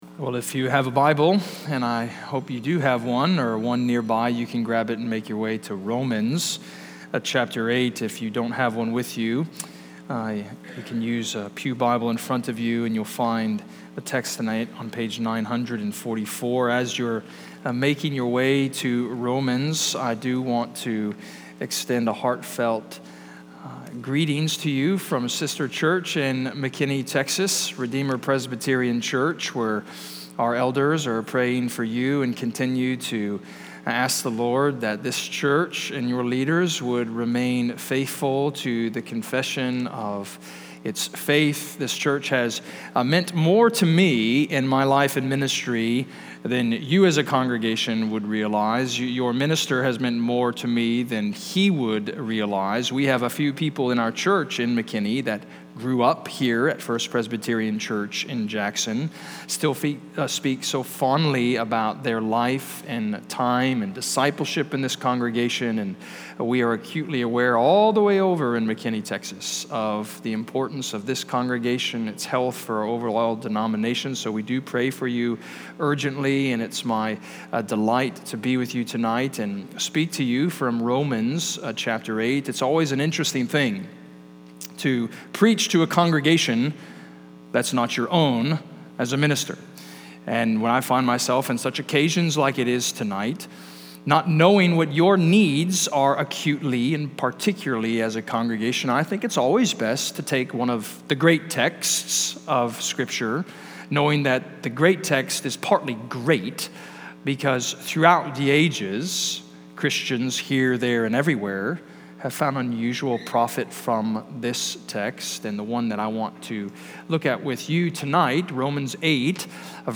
John Reed Miller Lecture (2025)